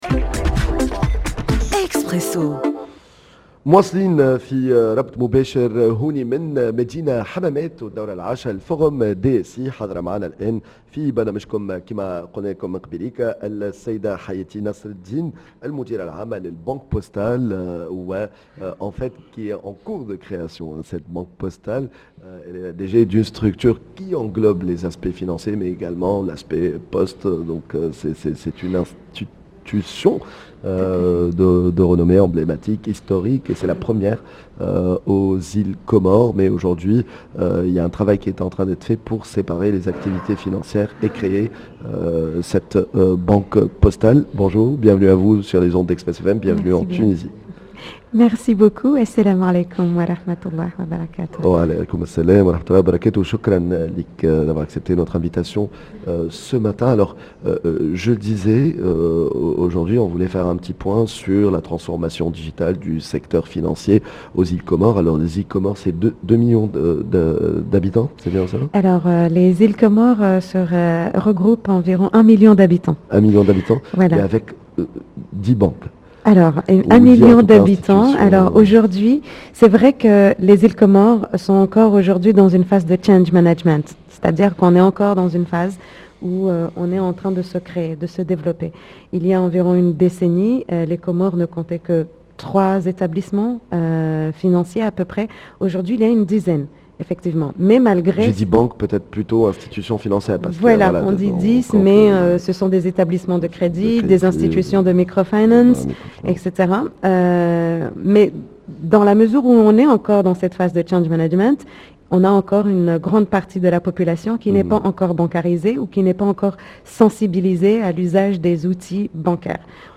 dans un plateau spécial en direct de Yasmine El Hammamet